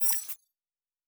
pgs/Assets/Audio/Sci-Fi Sounds/Weapons/Additional Weapon Sounds 4_1.wav at 7452e70b8c5ad2f7daae623e1a952eb18c9caab4
Additional Weapon Sounds 4_1.wav